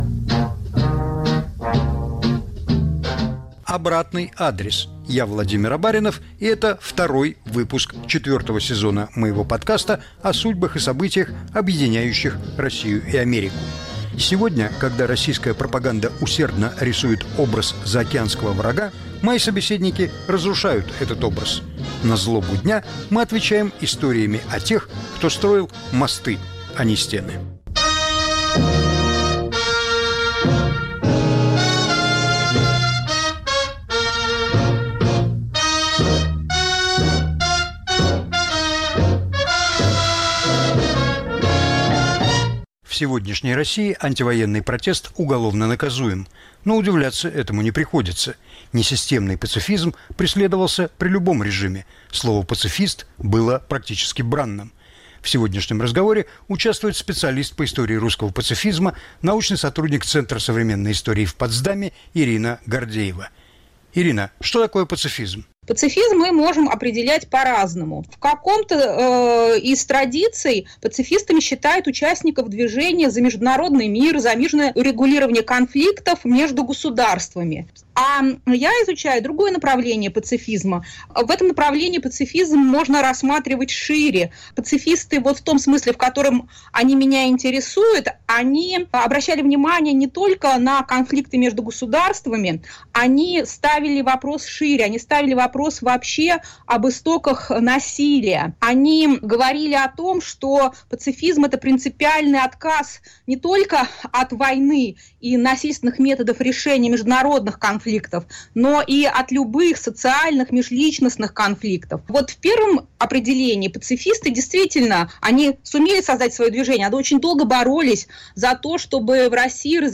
Повтор эфира от 23 сентября 2022 года.